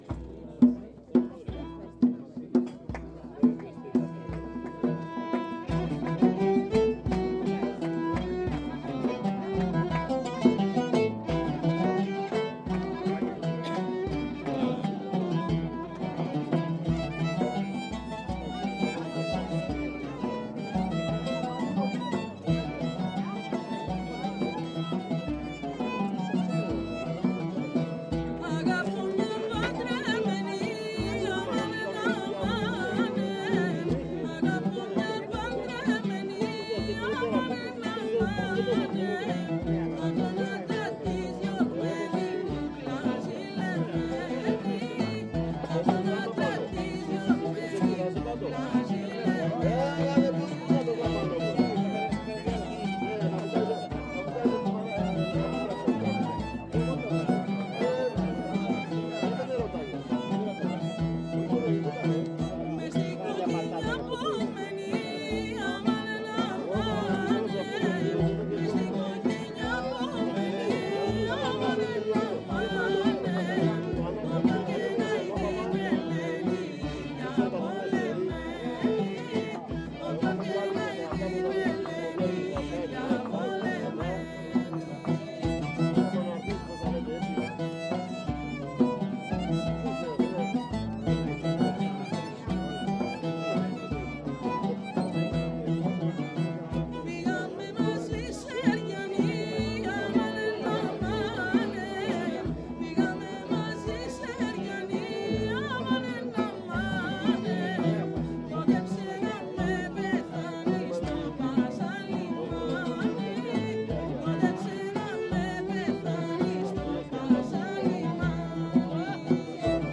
Un bout de montagne qui flotte sur la Méditerranée, des cigales qui hurlent, des oliviers contorsionnistes offrant leurs ombres tordues à des chèvres placides : bienvenue à Naxos.